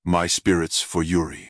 This product is the supporting voice of my self-made unit "Messiah Tank", which is completely self dubbed and not taken from any RTS in the same series.
These voices were completely recorded by me personally, with almost no post-processing, which makes them very versatile.
My spoken English may have a taste of Chinglish.